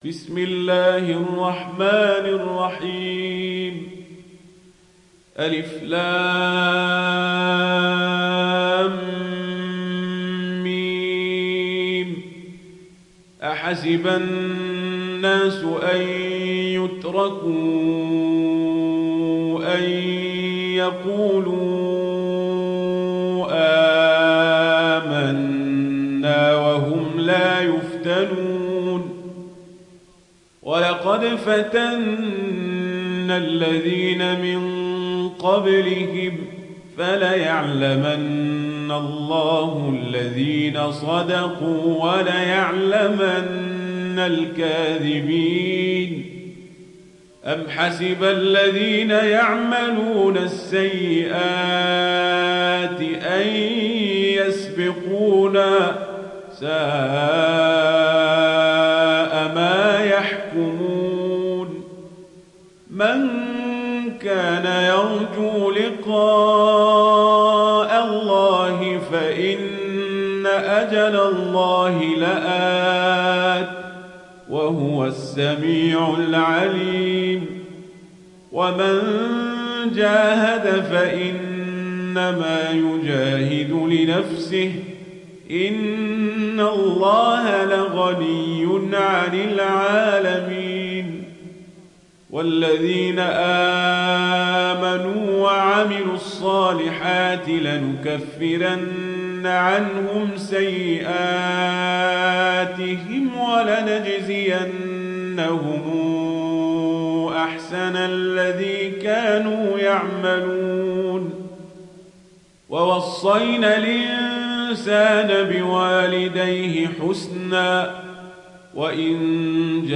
Ankebut Suresi mp3 İndir Omar Al Kazabri (Riwayat Warsh)